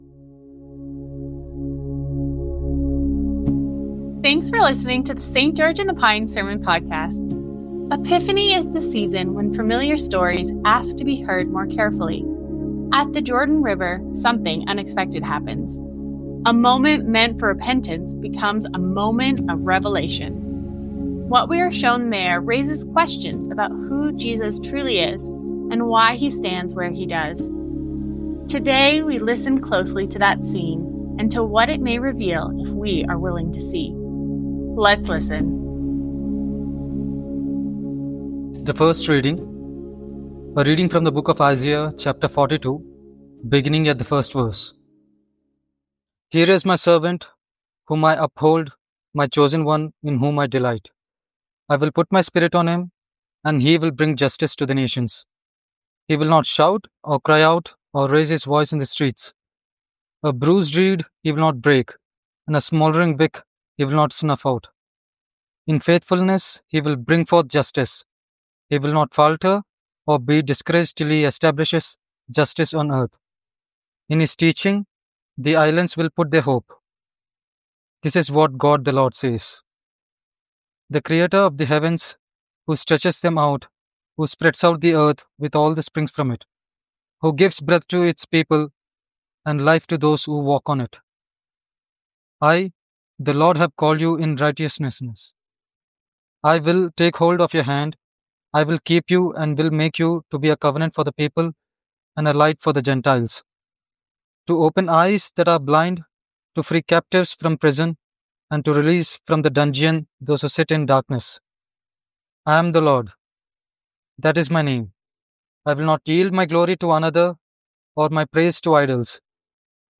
High Christology Isaiah 49:1-7; 1 Corinthians 1:1-9; John 1:29-42 Guest Speaker January 18, 2026 Current Sermon MANIFEST God’s glory is revealed in Jesus as he comes near to heal what was broken.